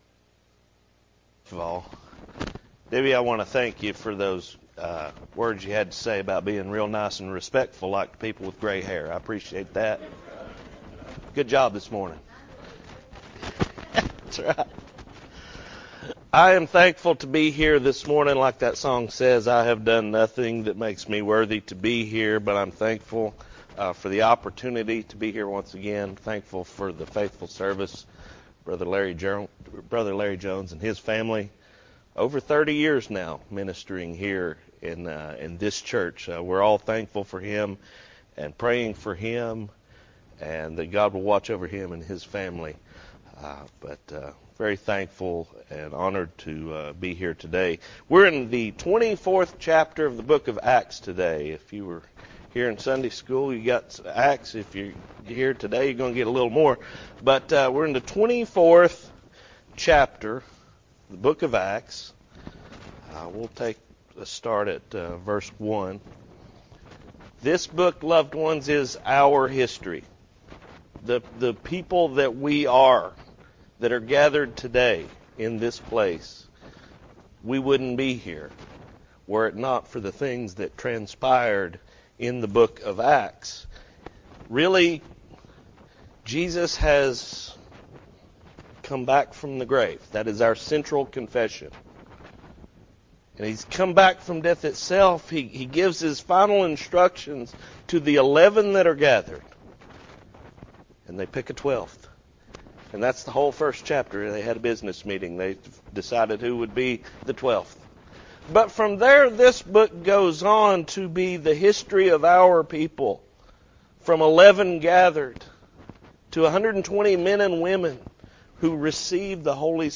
September 8, 2024 – Morning Worship